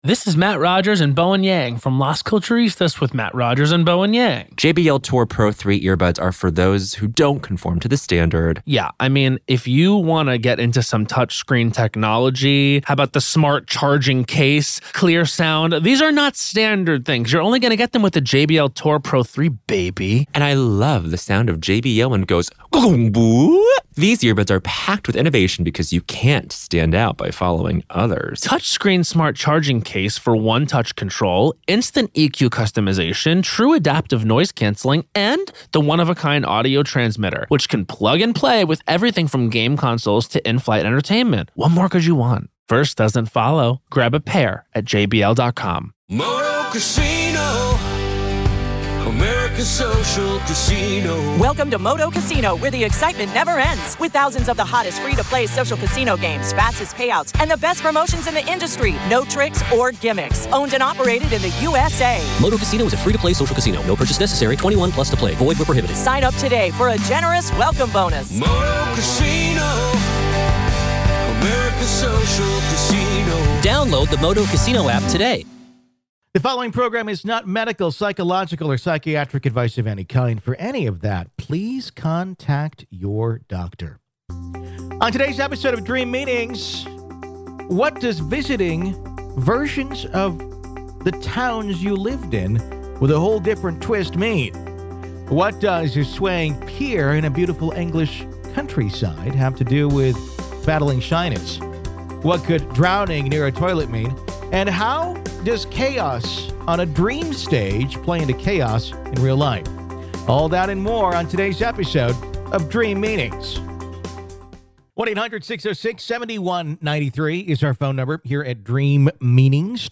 On Dream Meanings, we take your calls and read your letters about your dreams and give you our opinion and dream interpretation.